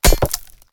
axe-mining-ore-4.ogg